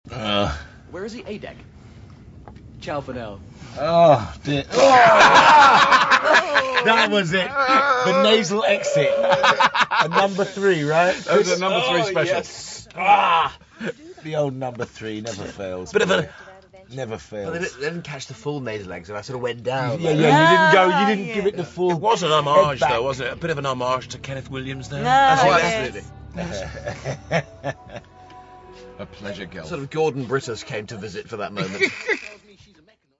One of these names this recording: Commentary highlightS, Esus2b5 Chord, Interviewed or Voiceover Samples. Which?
Commentary highlightS